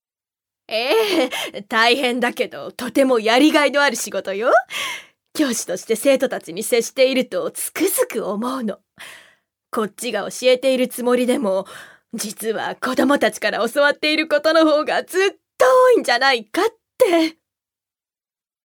女性タレント
音声サンプル
セリフ６